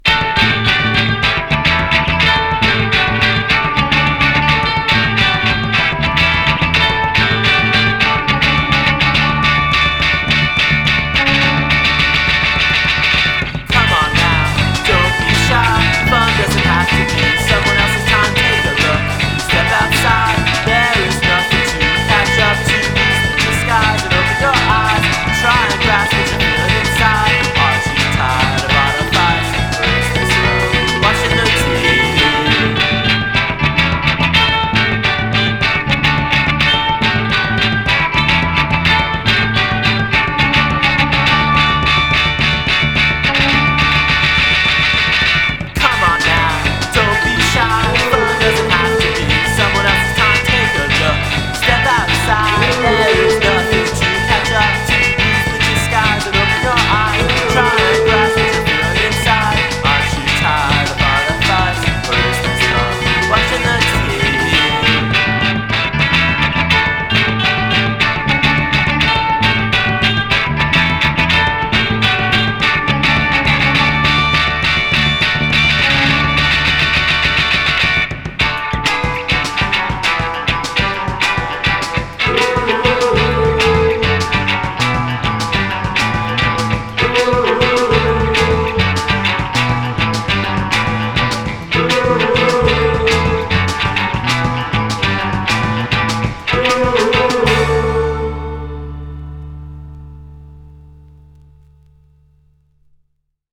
indiepop